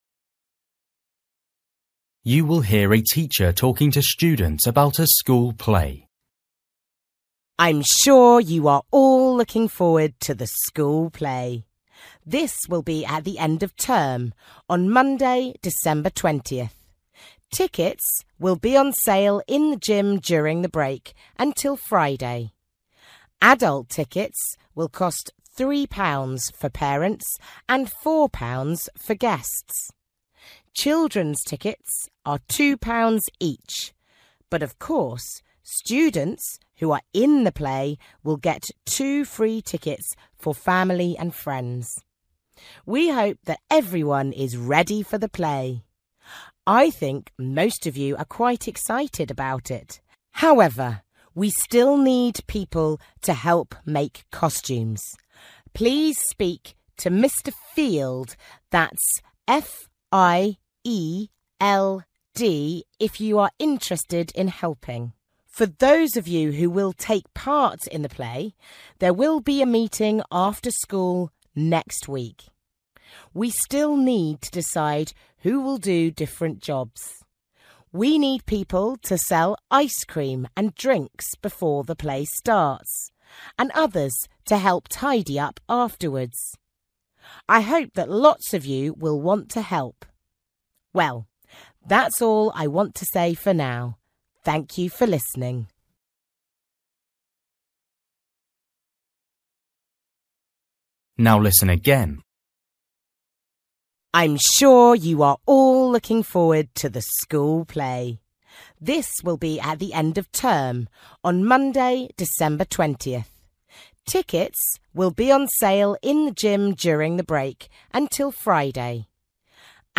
You will hear a teacher talking to students about a school play.